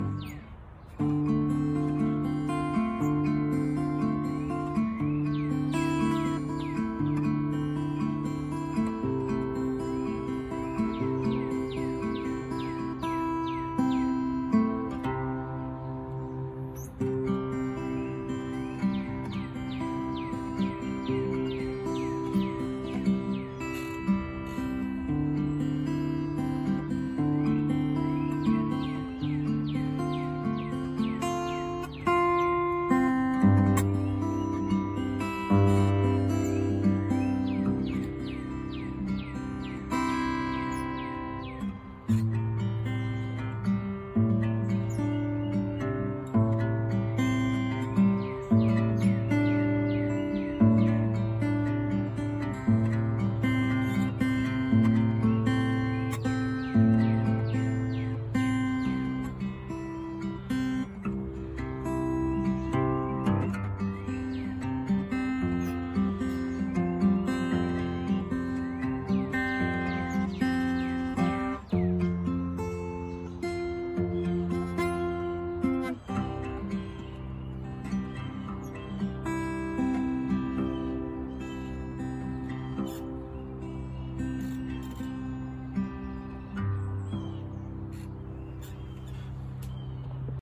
A while back I started teaching myself to play on my sister's guitar while she was off in college.
These short improvisations don't really constitute what I would consider a full song, but rather things I may someday incorporate into music if I ever produce or compose.